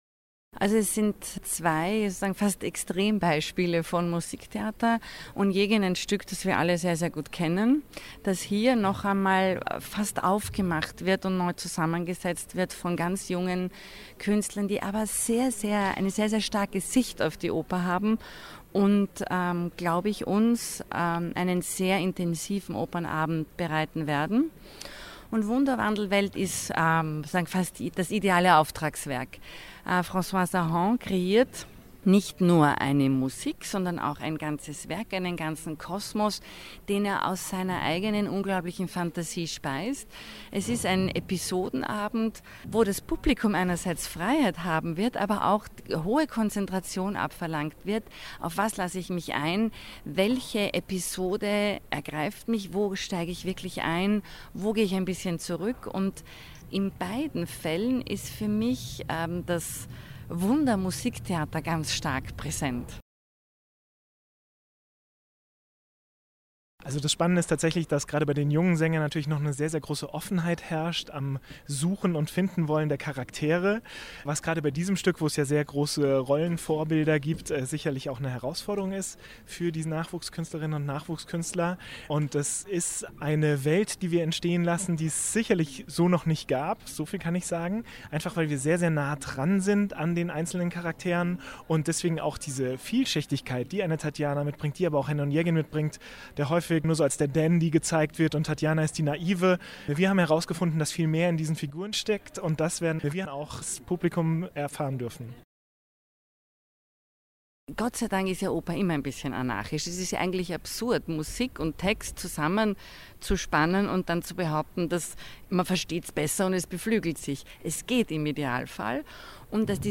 Pressetag II - O-Ton-Service